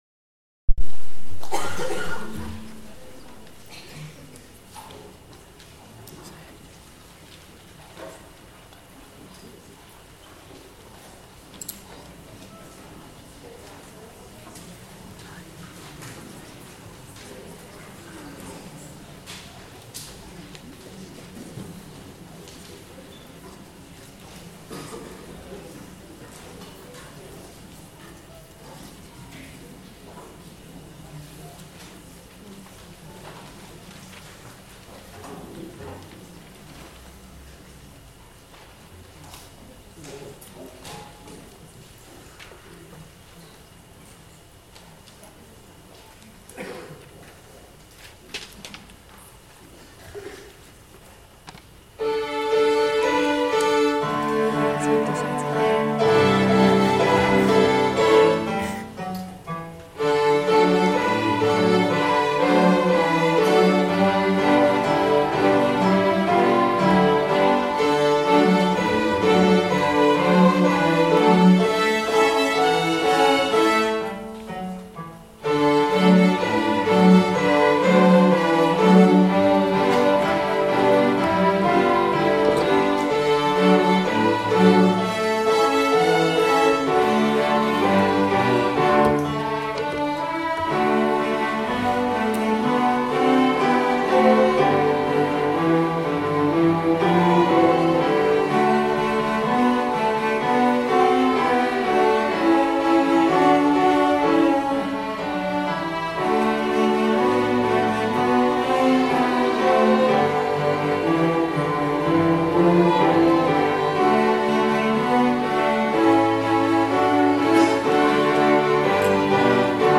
string ensemble (both)